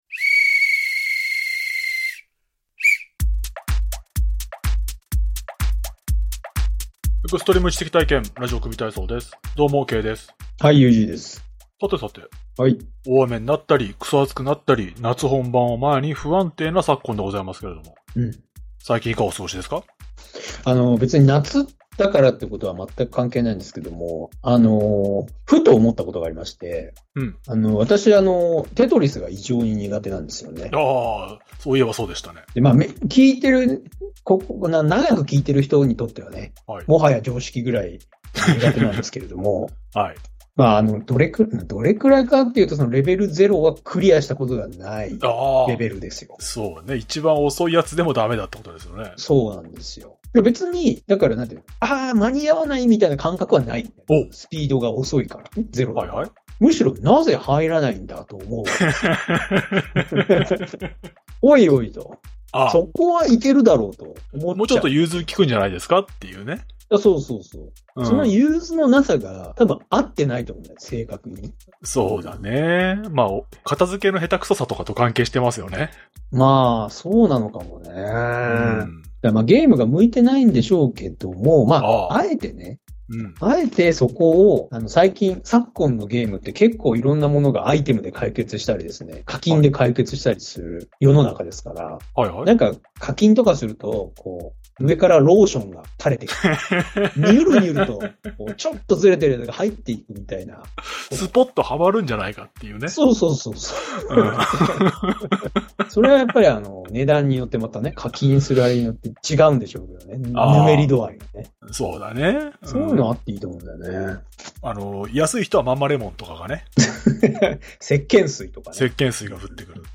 （実行者の特徴：年配男性、白色口ひげ） 💬 エンディング 01:01:31 ボクシングの対戦相手が別人だったかもしれない件 (2023年07月05日収録) 番組ではネタのタレコミ、ご意見、ご感想を募集しています。